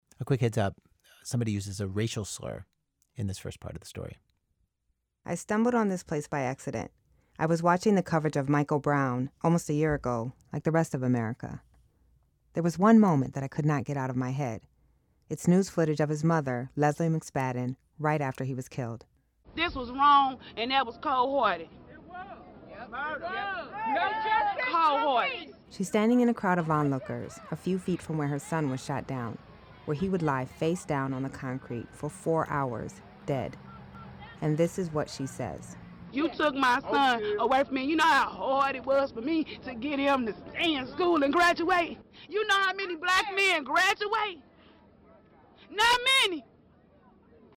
This clip is from the ‘This American Life,’ piece called “The Problem we all live with. The clip is introduced and narrated by Nikole Hannah-jones.